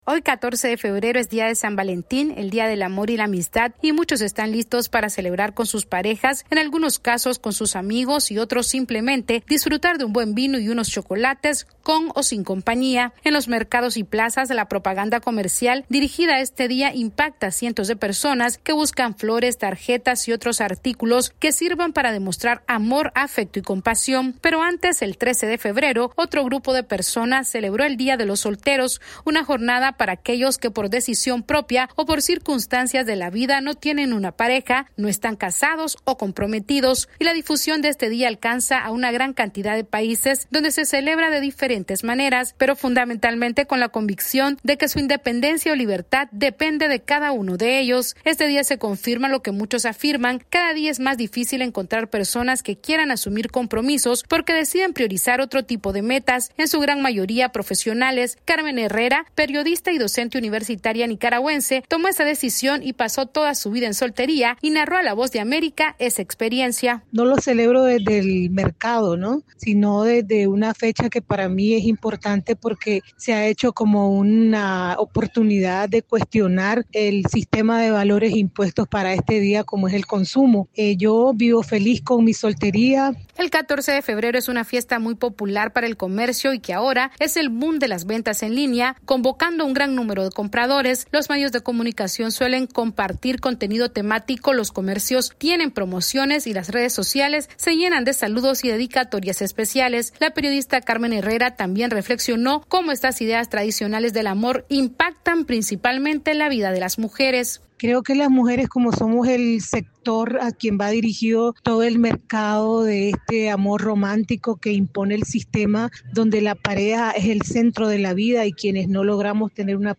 AudioNoticias
Cientos de personas festejarán hoy, 14 de febrero, el Día de San Valentín, mientras otro grupo de personas habla hoy del Día de los Solteros que se celebró el 13 de febrero, y en ambos casos se reconoce el amor y la amistad.  Esta es una actualización de nuestra Sala de Redacción.